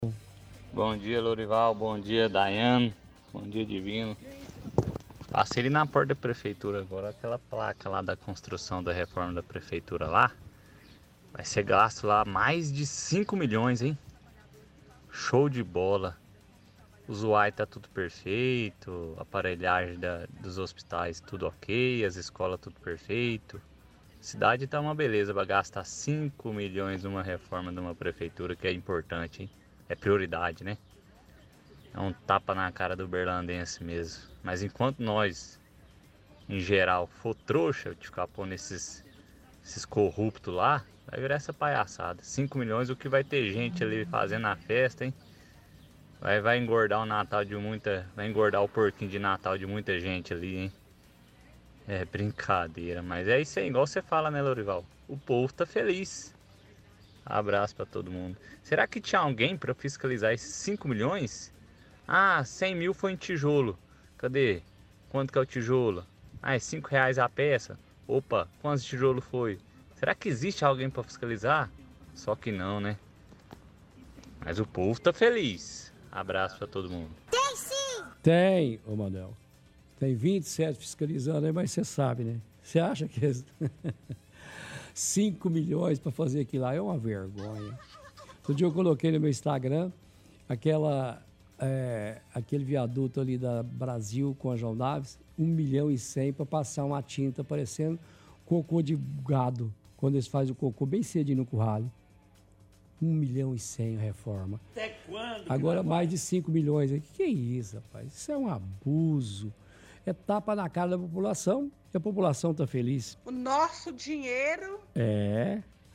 – Ouvinte fala que passou na porta da prefeitura e viu a placa que mostra o investimento total na reforma do centro administrativo.